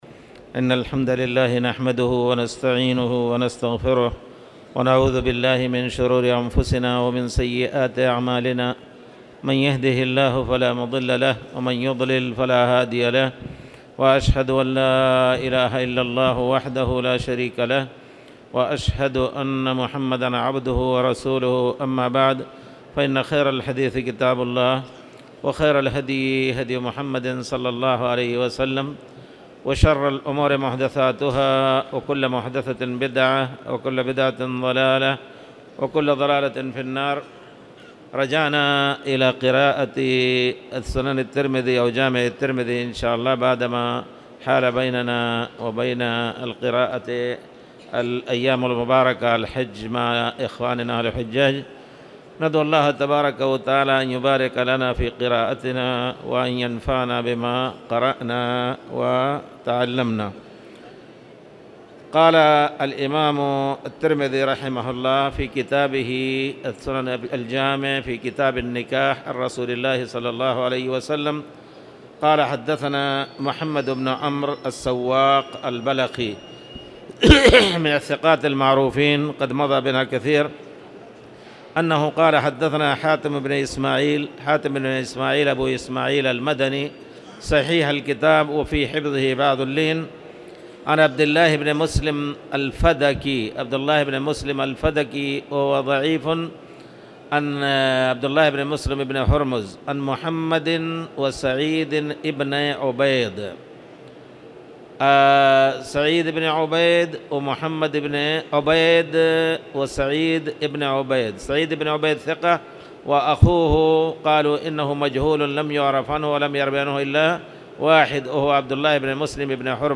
تاريخ النشر ١٩ ذو الحجة ١٤٣٧ هـ المكان: المسجد الحرام الشيخ